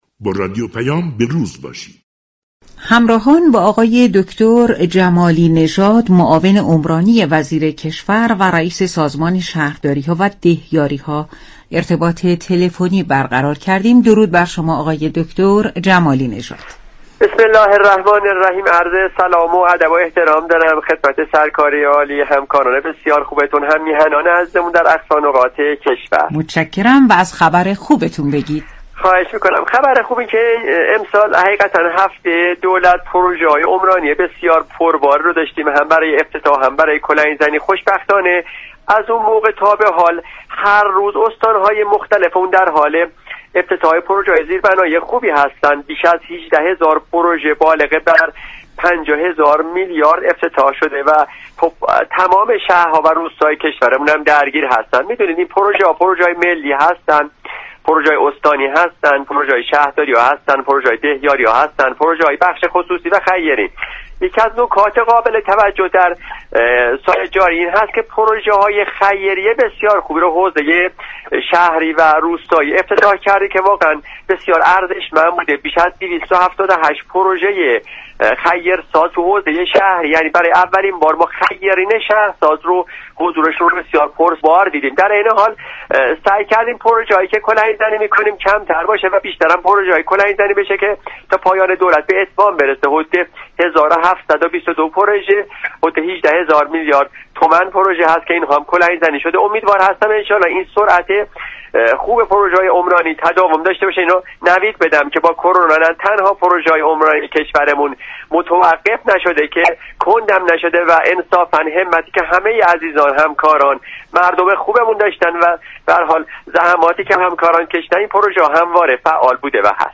جمالی نژاد ، معاون عمرانی وزیر كشور و رئیس سازمان شهرداریها و دهیاریها ، در گفتگو با رادیو پیام ، از افتتاح ٢٧٨ پروژه زیربنایی در حوزه شهری ، كه برای اولین بار توسط خیرین شهرساز ساخته شده‌اند خبر داد .